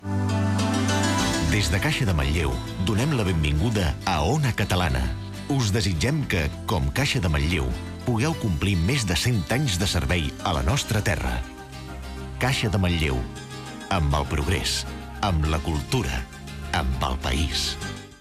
Caixa de Manlleu dona la benvinguda a Ona Catalana Gènere radiofònic Publicitat